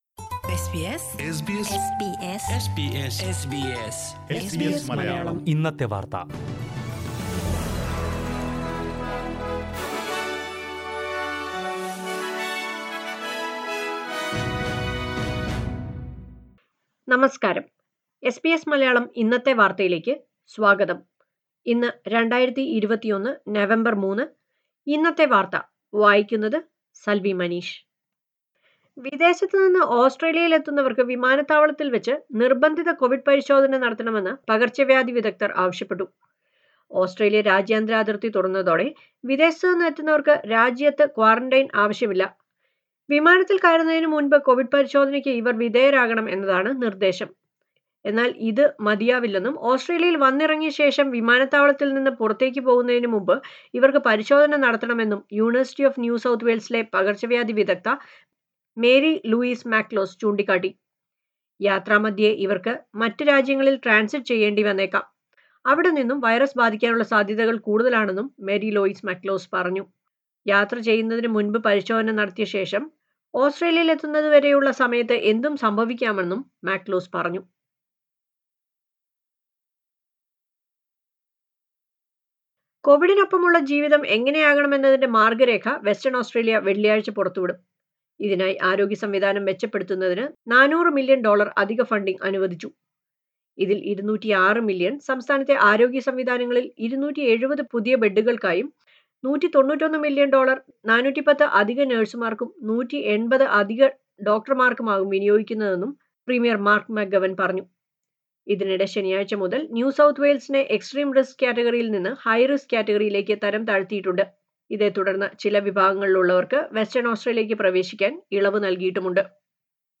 SBS Malayalam Today's News: November 3, 2021